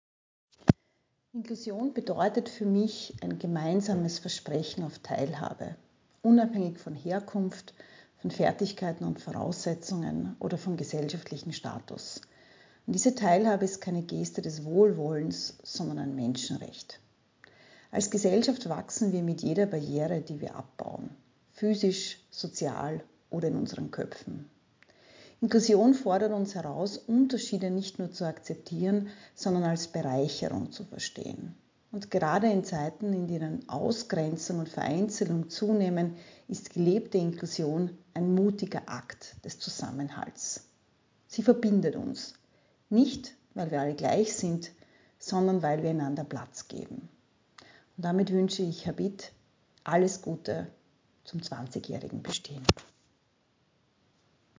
Bewegende Botschaften zum Thema Inklusion, gesprochen von Menschen aus Kunst, Kultur, Politik und Wissenschaft.